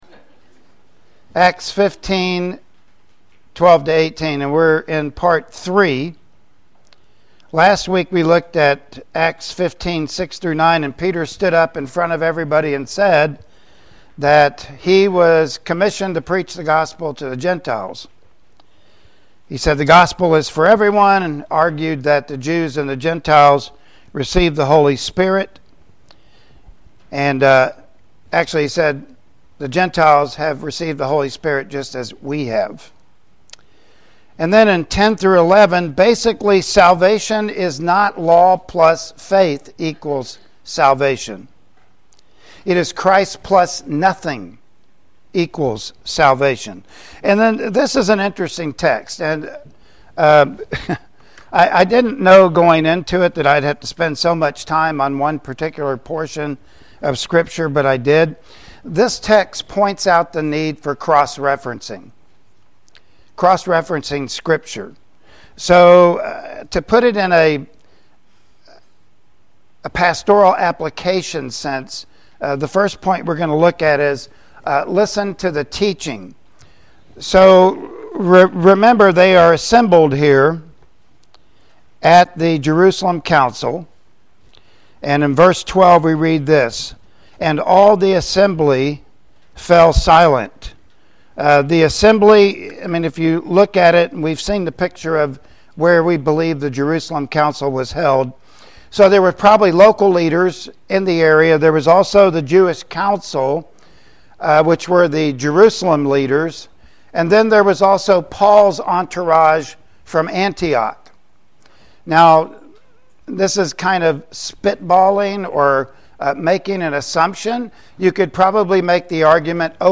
Passage: Acts 15:12-18 Service Type: Sunday Morning Worship Service Topics